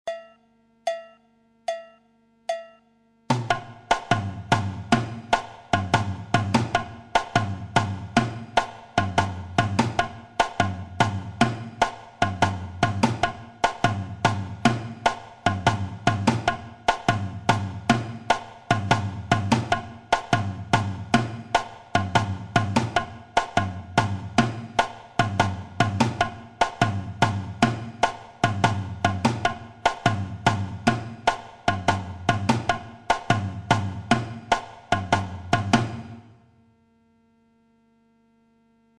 Section rythmique phrasé samba
batida avec une syncope